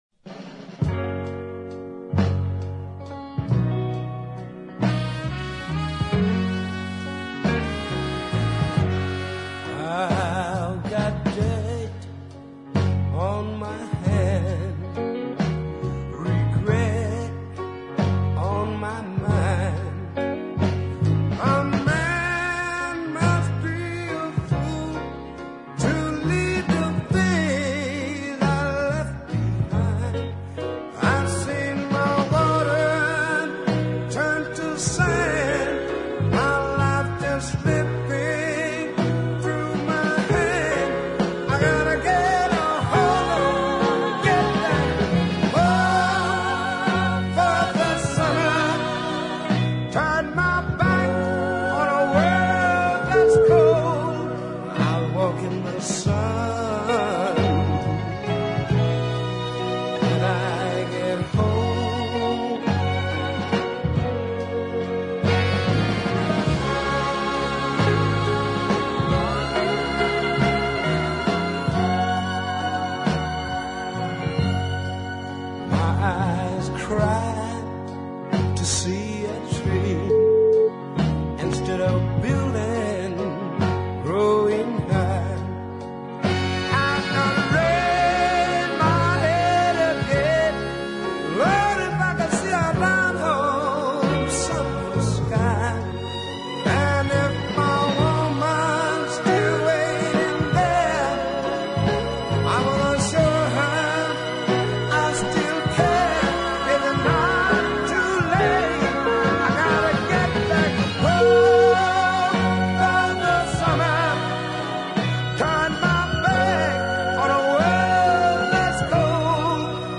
attractive light baritone voice
The dead slow